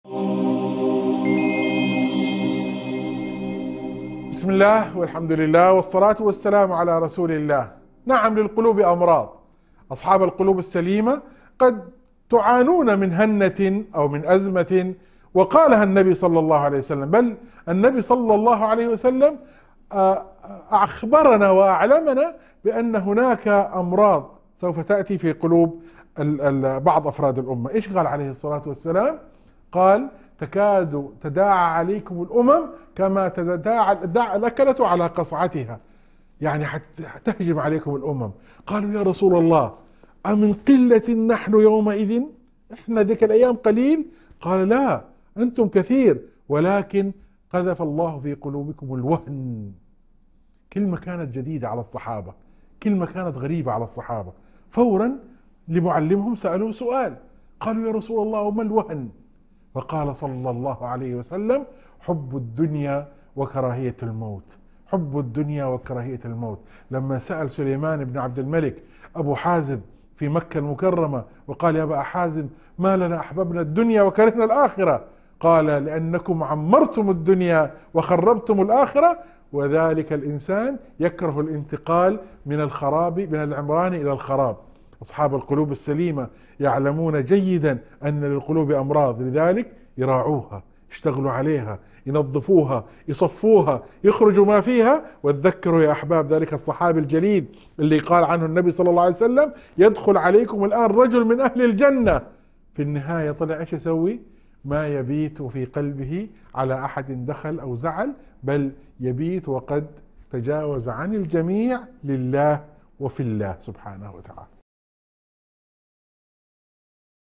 موعظة عن أمراض القلوب وخطورة حب الدنيا وكراهية الموت، مع ذكر حديث النبي صلى الله عليه وسلم في الوهن. يتضمن الحديث عن تزكية النفس وتصفية القلب، مستشهداً بمواقف من سيرة الصحابة كنماذج للقلوب السليمة والعفو عن الناس.